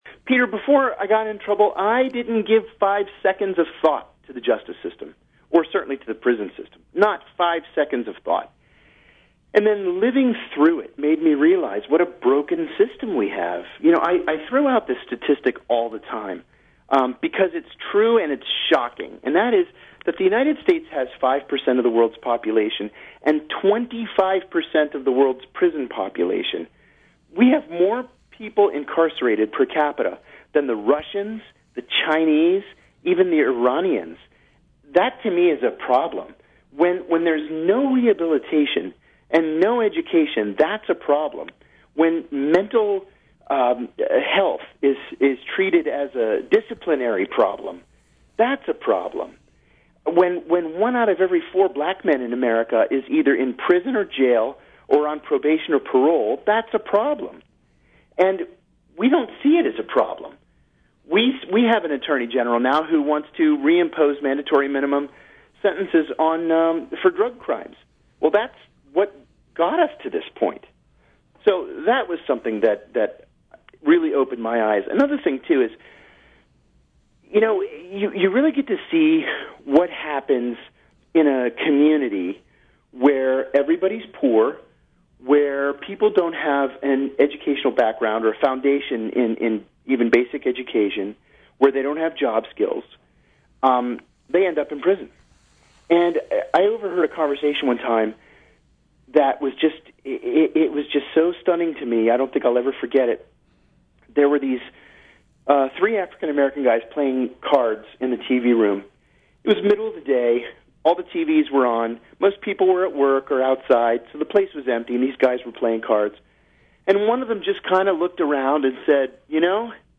In-Depth Interview: Former CIA Officer John Kiriakou Talks About His Prison Experience